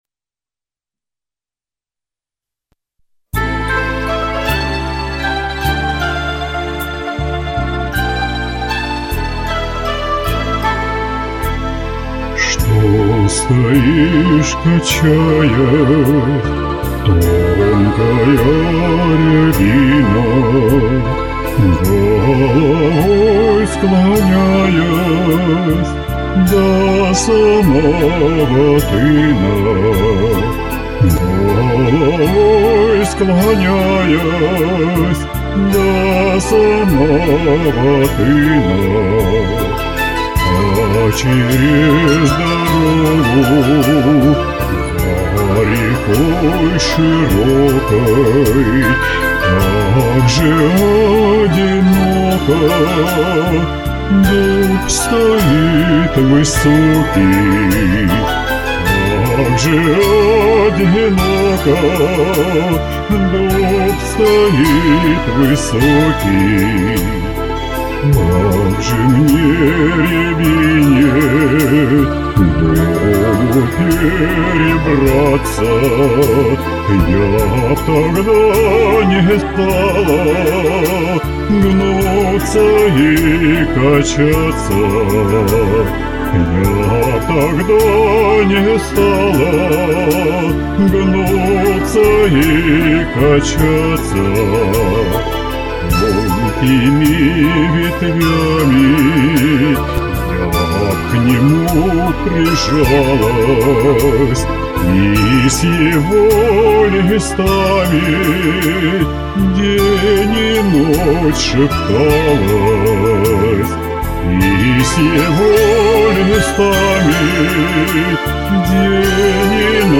Это же все-таки вполне лирическая песня, ее надо распевать.